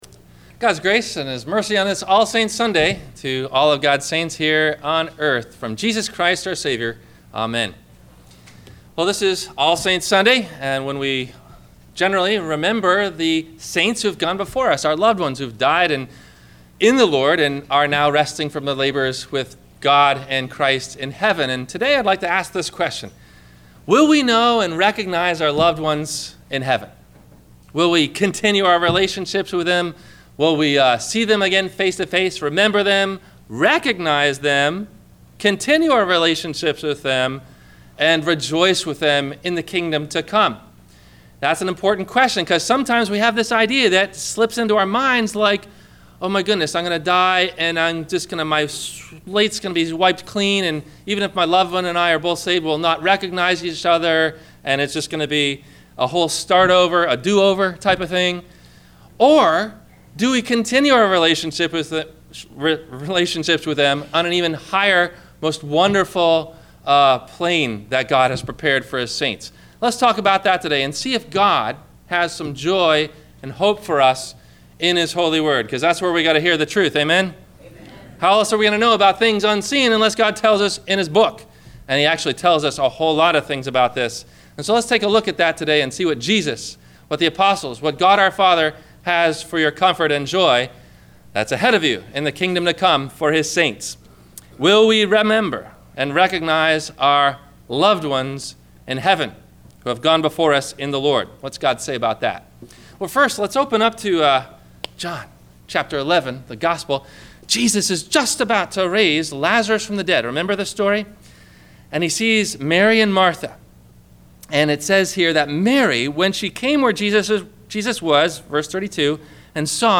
Jesus Appeared By The Sea – Sermon – May 04 2014